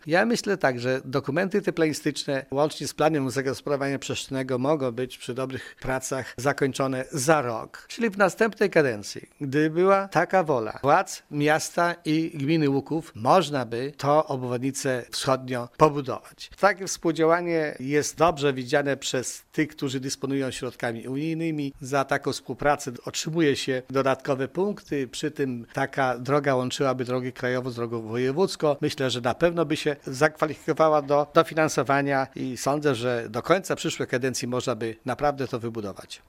Według wójta Osika nowa obwodnica mogłaby powstać w ciągu najbliższych 4 lat. Wszystko zależy jednak od woli władz miasta i gminy nowej kadencji: